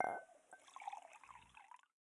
描述：有人往茶杯里倒热水的声音，接着是勺子搅拌杯中茶水的声音。使用奥林巴斯VN 480录音机录制。